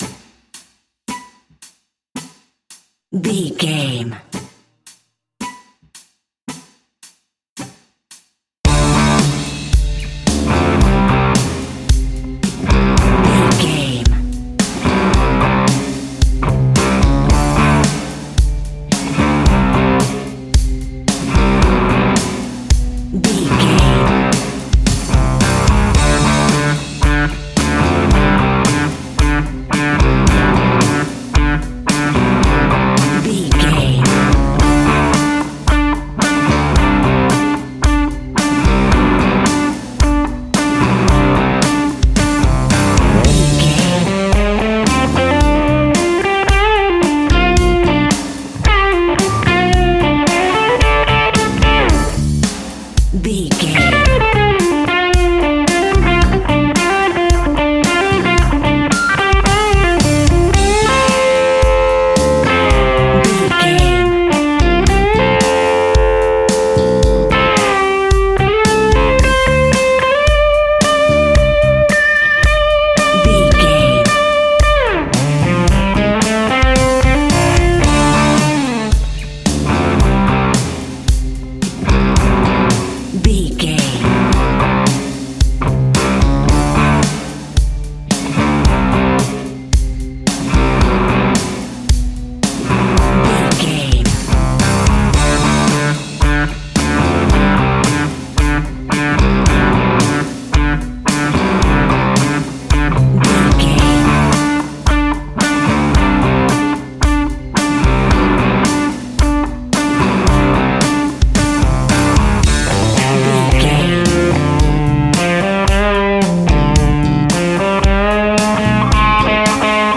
Ionian/Major
D
energetic
driving
aggressive
electric guitar
bass guitar
drums
hard rock
heavy metal
blues rock
distortion
heavy drums
distorted guitars
hammond organ